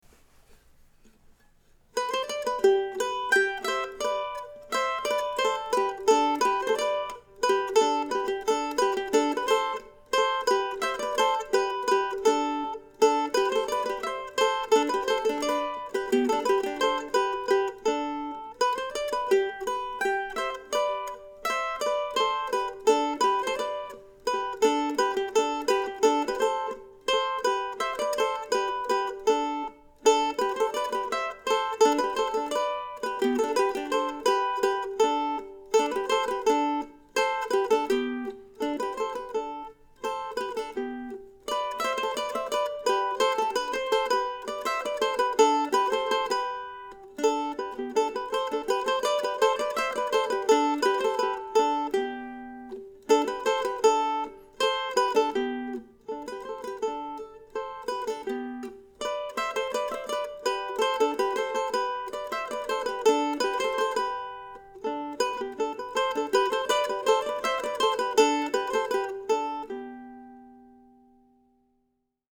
I also recorded the third of William Bates' duettinos, another fun piece to play.